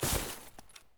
grass1.ogg